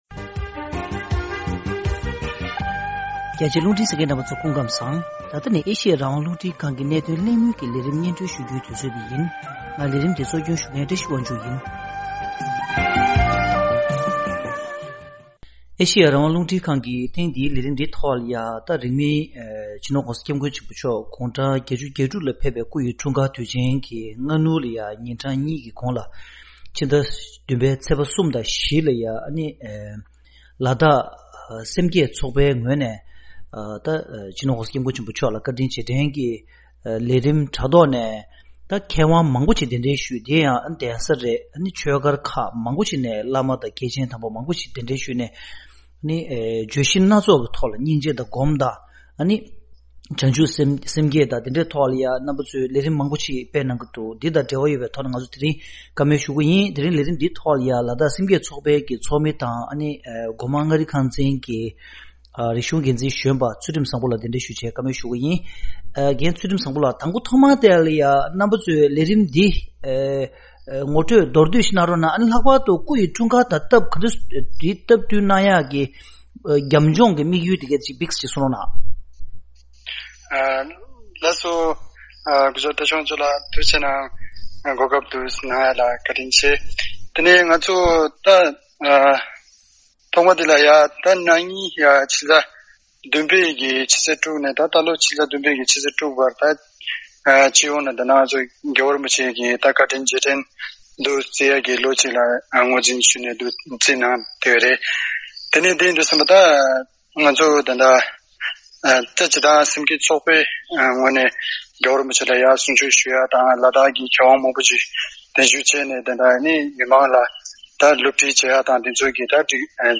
གནད་དོན་གླེང་མོལ་གྱི་ལས་རིམ།